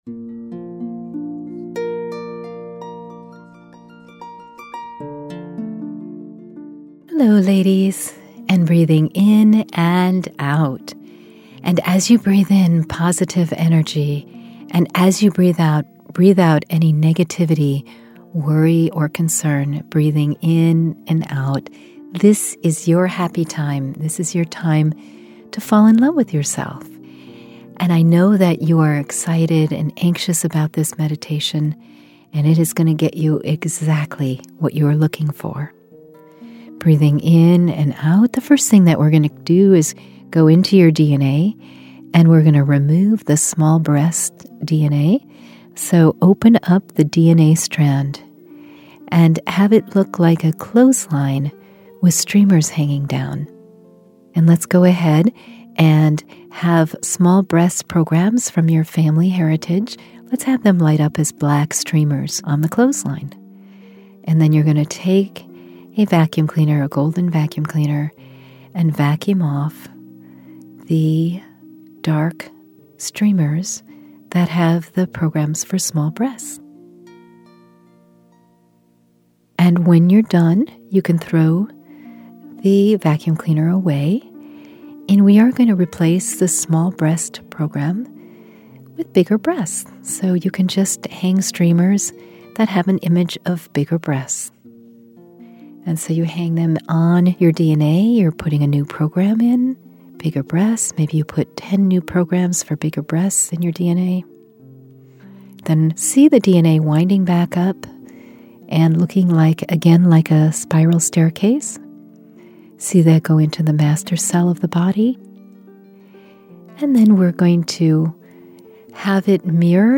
If you are a slender woman with a tiny chest or have a genetic predisposition for small breasts you will love this helpful meditation. It gives you the triggers and the resets to grow your breasts to the size and shape you want.